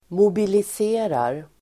Uttal: [mobilis'e:rar]